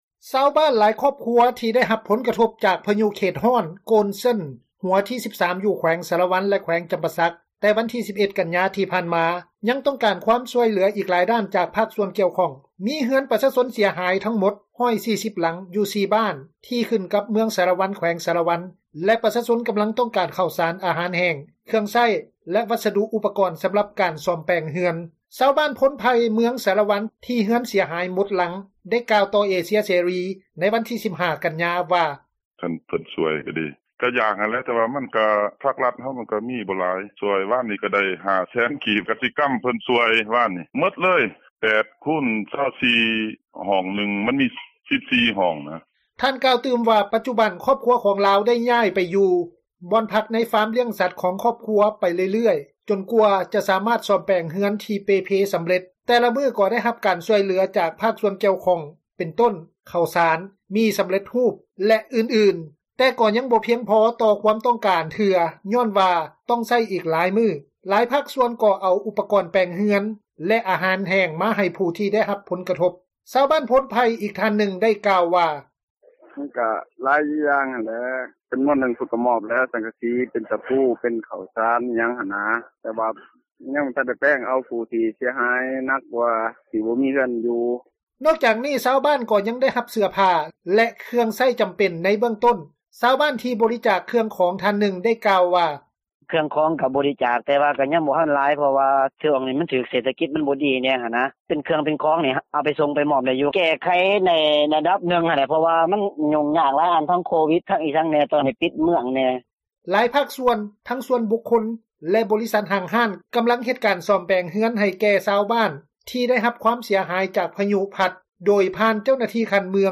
ຊາວບ້ານໂພນໄຜ່ ເມືອງສາຣະວັນ ທີ່ເຮືອນເສັຽຫາຍໝົດຫຼັງ ໄດ້ກ່າວຕໍ່ ເອເຊັຽເສຣີ ໃນວັນທີ 15 ກັນຍາ ວ່າ: